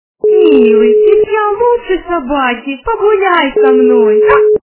» Звуки » Люди фразы » голос девушки - Милый, ведь я лучше собаки
При прослушивании голос девушки - Милый, ведь я лучше собаки качество понижено и присутствуют гудки.
Звук голос девушки - Милый, ведь я лучше собаки